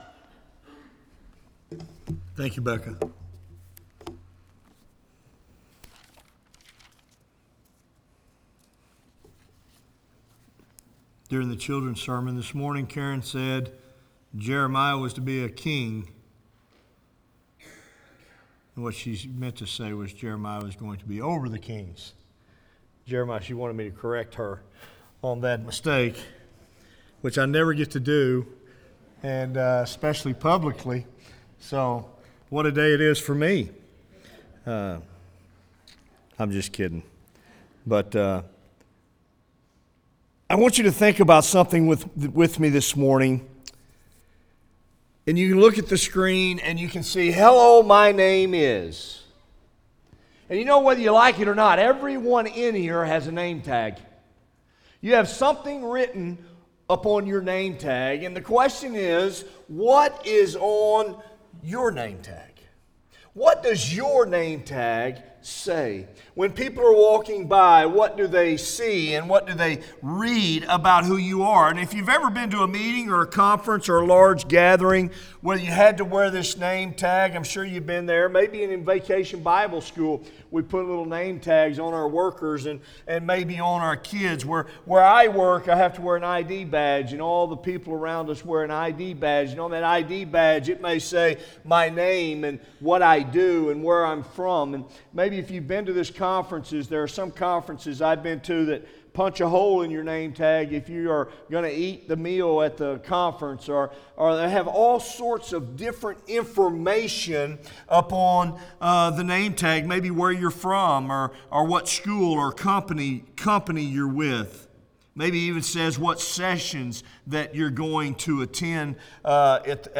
by Office Manager | May 24, 2017 | Bulletin, Sermons | 0 comments